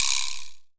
Perc [Flowers].wav